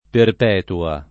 perp$tua] pers. f. stor.